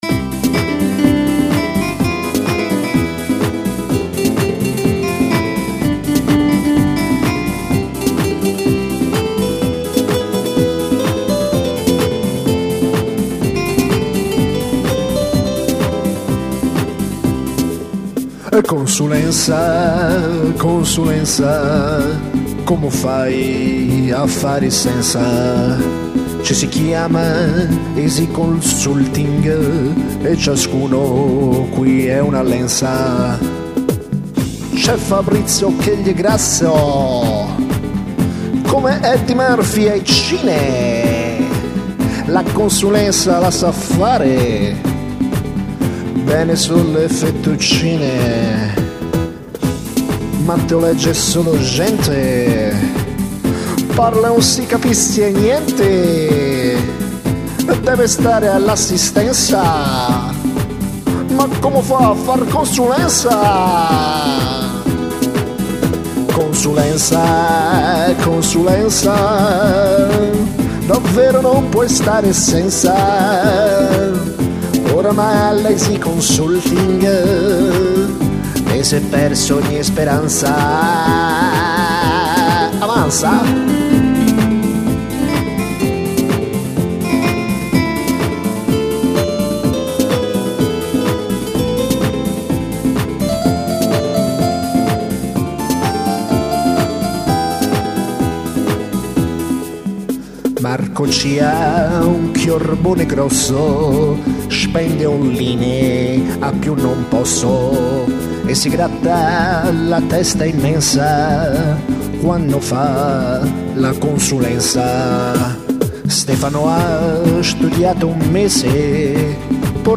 Canzone discretamente trash.